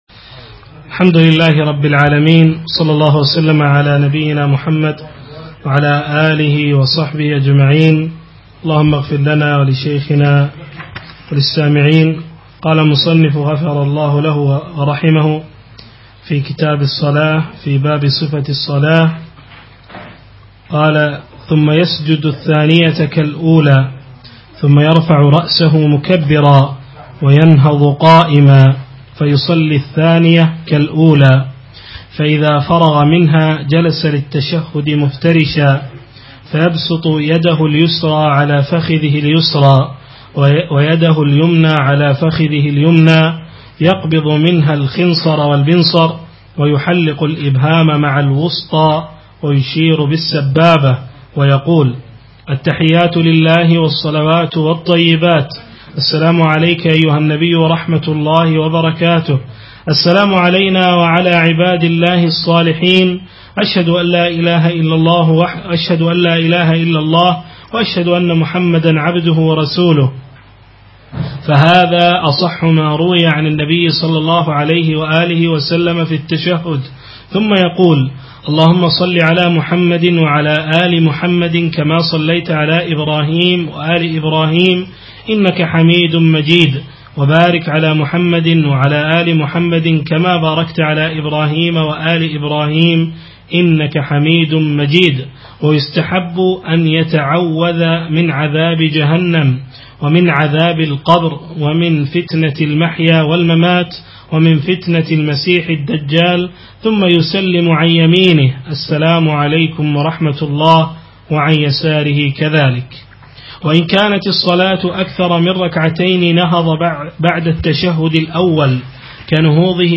أقيمت الدورة في دولة قطر من يومي الجمعة والسبت 19 و 20 صفر 1436 الموافق 11 و 12 من شهر ديسمبر 2014
الدرس الخامس